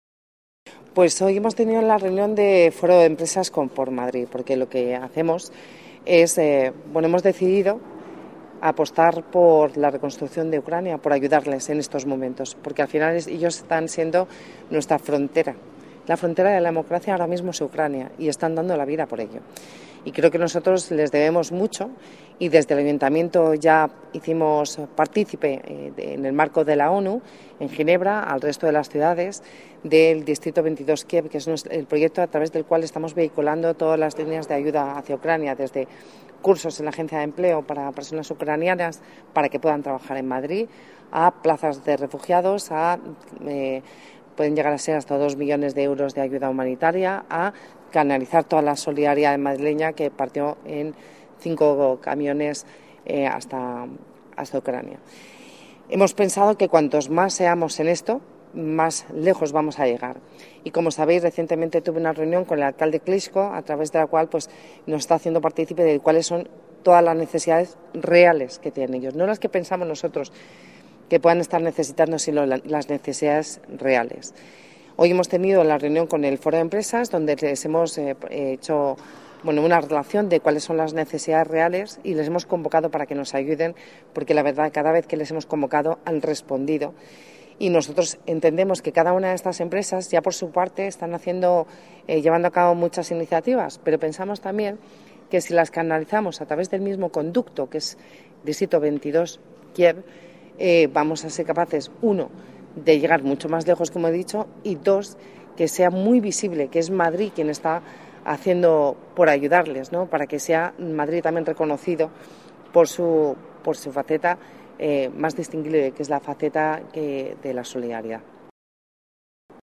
Nueva ventana:Declaraciones de la vicealcaldesa, Begoña Villacís